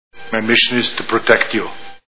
Terminator 3 Movie Sound Bites